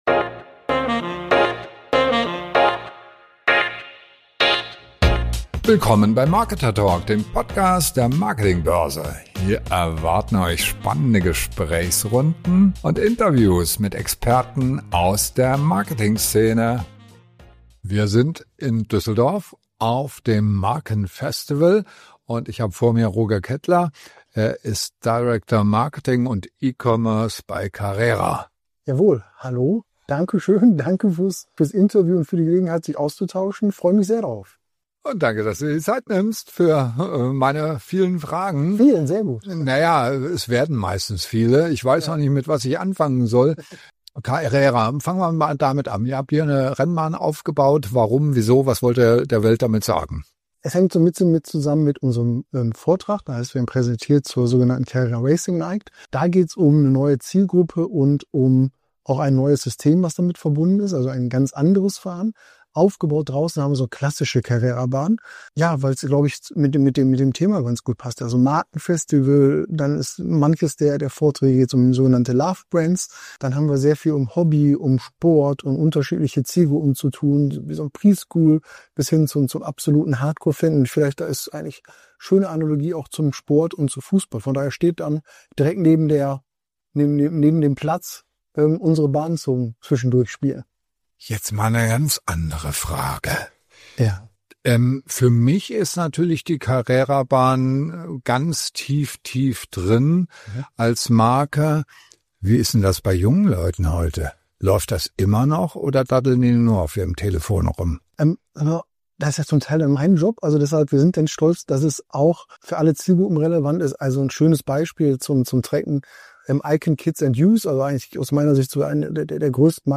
Love Brand statt reines Spielzeug: Wie Carrera über Generationen hinweg Fans generiert und gleichzeitig mit KI-Innovation neue Zielgruppen erschließt – ein Interview über authentisches Engagement jenseits von Marketing-Floskeln.